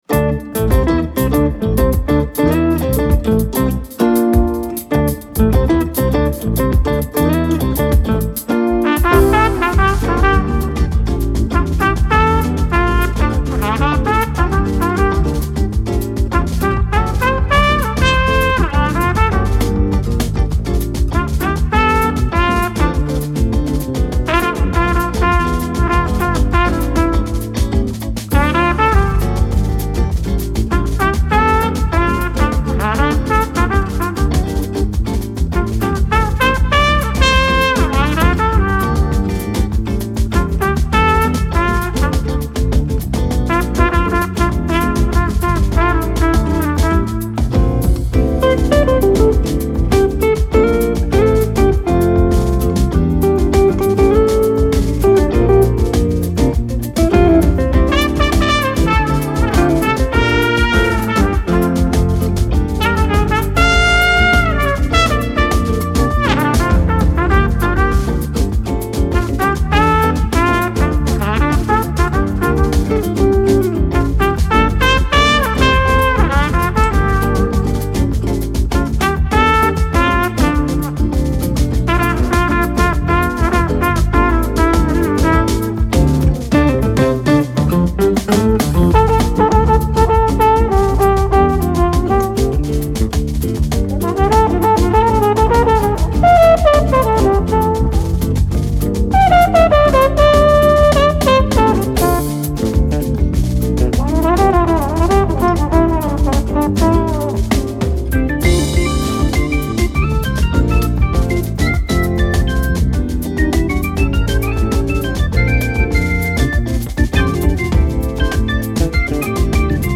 Genre : Contemporary Jazz